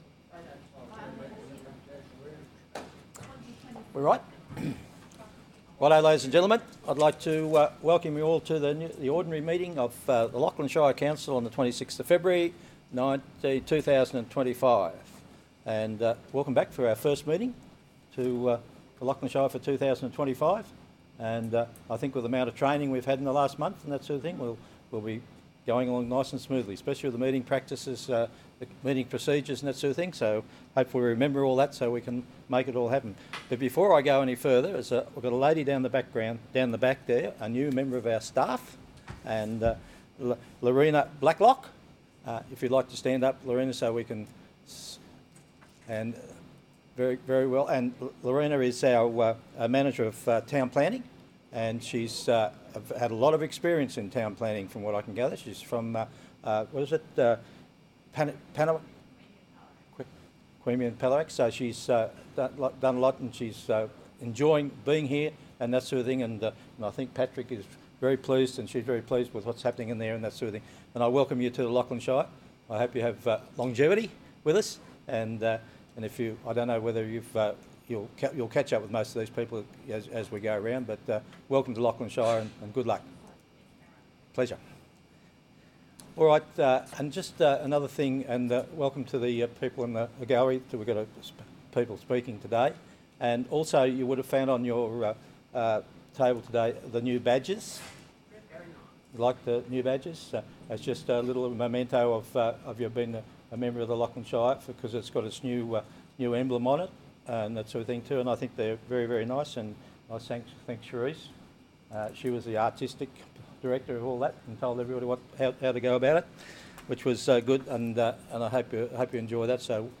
The February 2025 meeting will be held in the Council Chambers at 2:00pm and is open to the public.